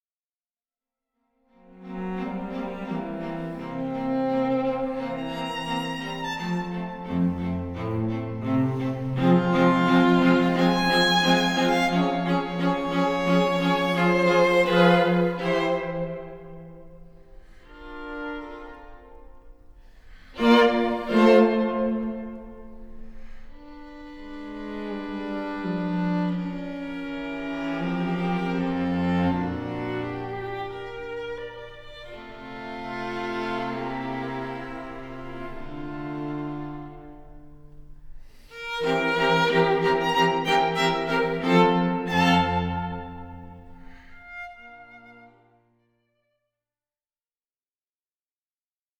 Adagio (1.29 EUR)